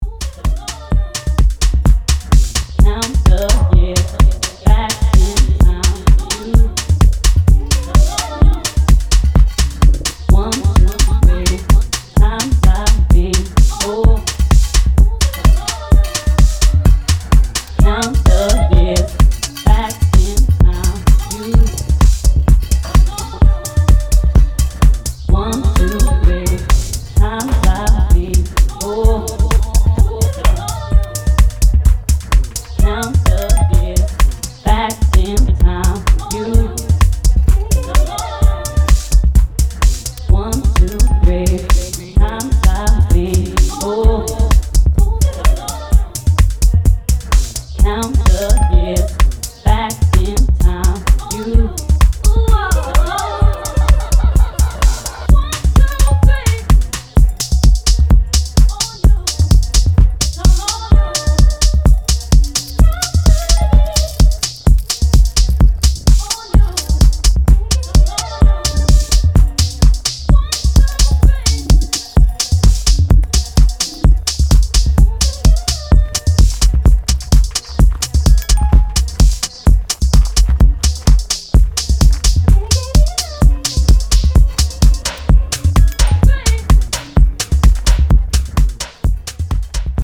ソウルフルな女性Vo.やポリリズミックなサンプルを配しハメ度の高い危険な一曲を仕上げてきました！